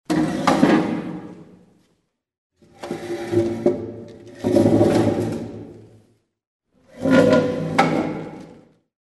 Звуки стула
Скрип табуретки по кафельному полу